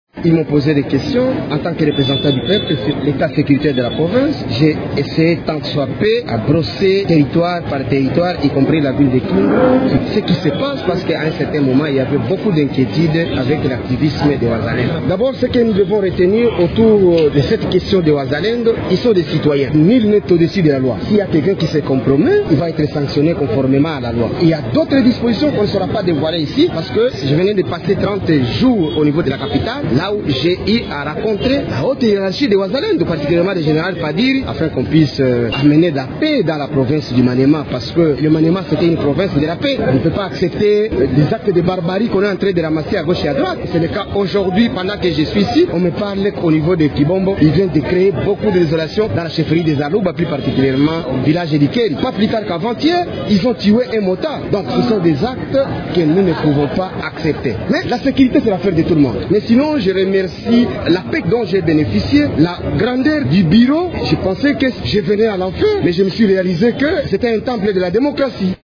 Une motion d’information a été initiée, conduisant le ministre à se présenter devant les députés provinciaux.
Face aux élus du peuple, Taylor Lawamo Selemani a affirmé avoir engagé des discussions avec la haute hiérarchie des Wazalendo afin de trouver une solution appropriée à cette crise sécuritaire :